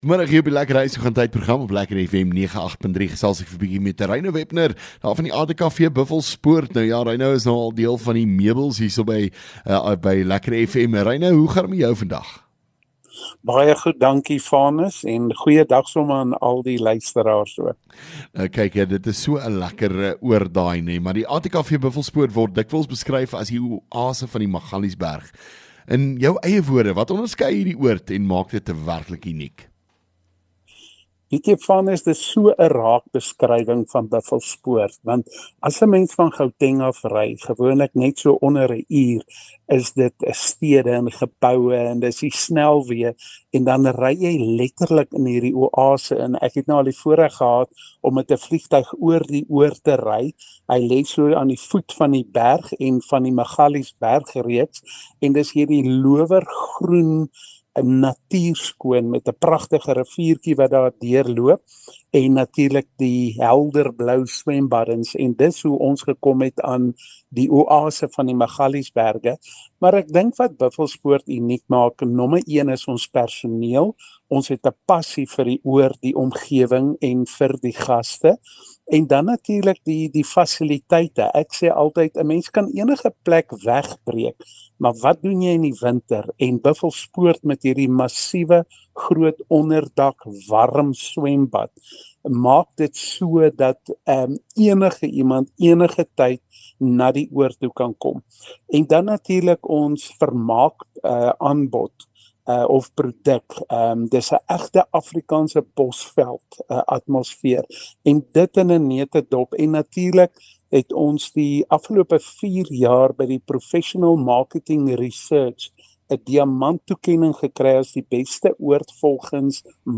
LEKKER FM | Onderhoude 25 Apr ATKV-Buffelspoort